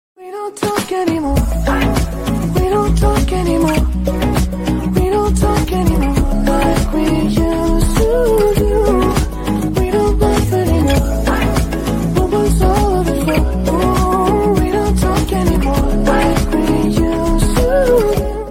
🎶 Kitten’s Musical Meows are sound effects free download
🎶 Kitten’s Musical Meows are the Sweetest Sound! 🐾 A melody so pure, you won’t stop smiling!